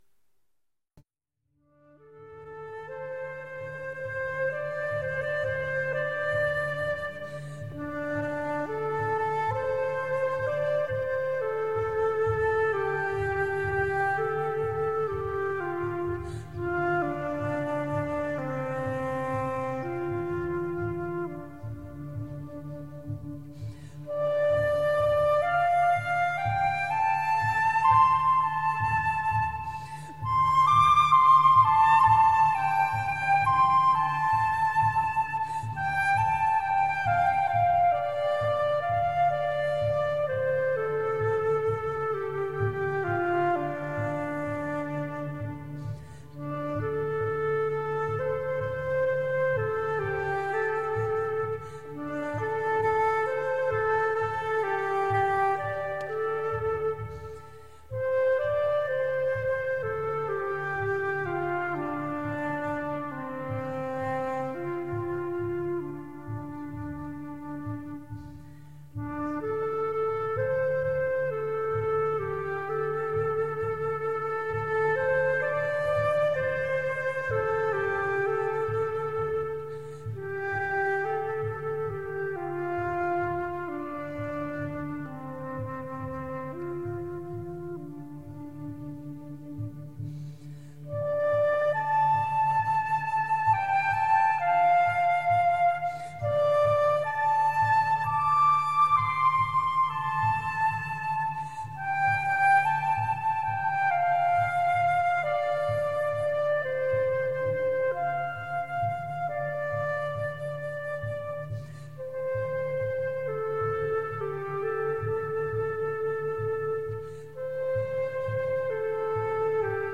Virtuosic flutist.
Soothing and luminescent soliloquies for flute.
Tagged as: World, New Age, Instrumental, Flute, Massage